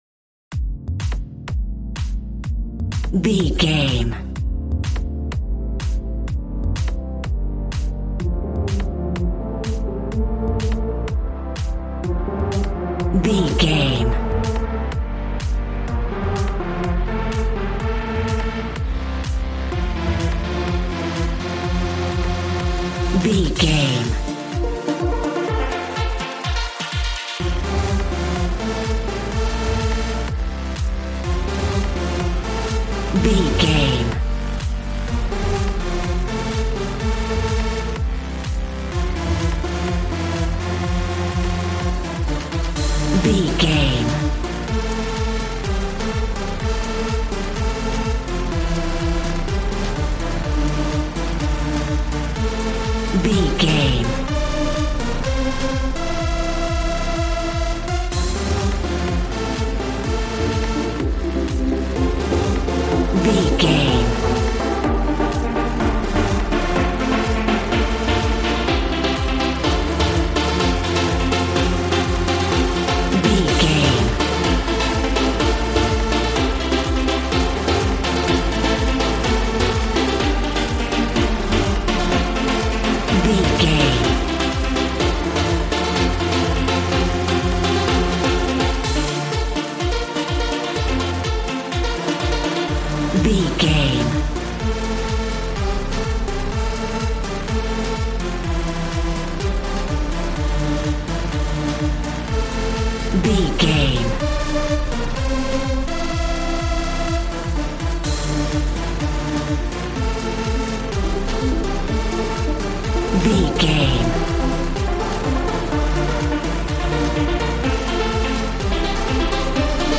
Ionian/Major
Fast
groovy
energetic
synthesiser
drums